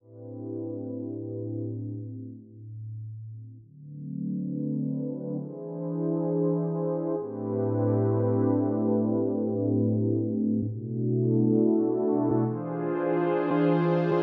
Am_135_Jazz Pad.wav